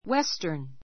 western wéstə r n ウェ ス タン